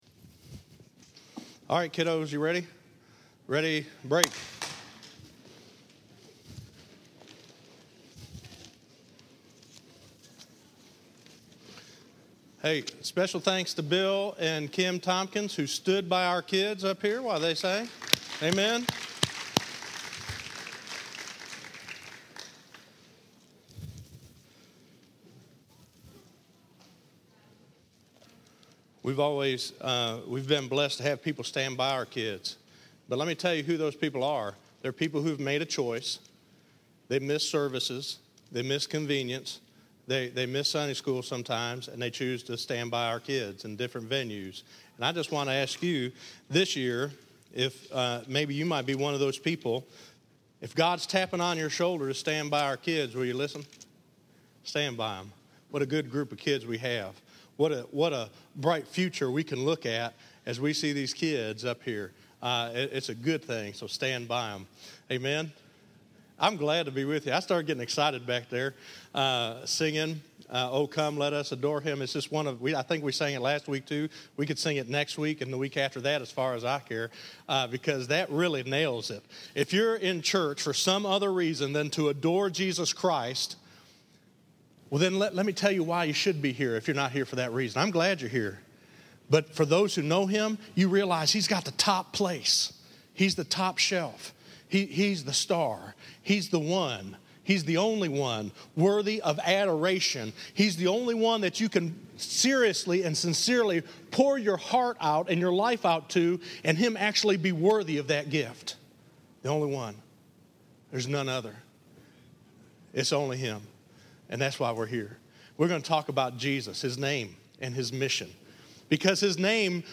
Listen to Jesus His Name Is His Mission - 12_21_14_Sermon.mp3